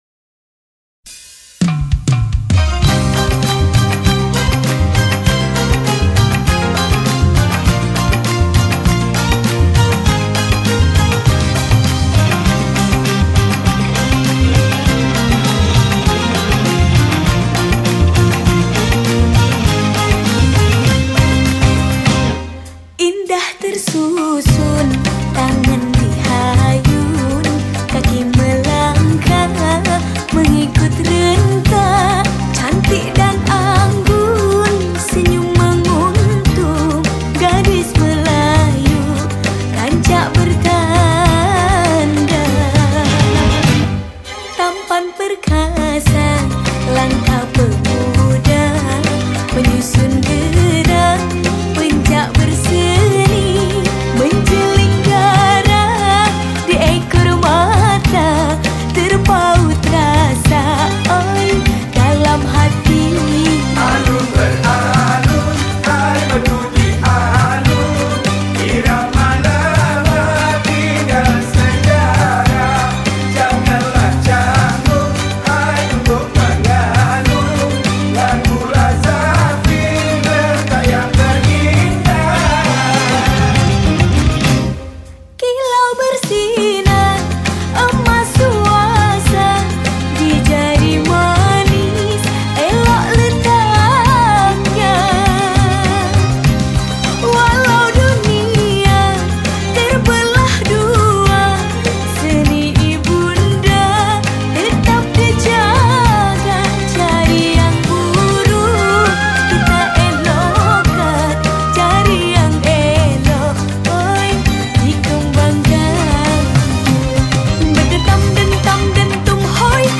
Instrumen                                 : Vokal
Genre Musik                            : Dangdut, Pop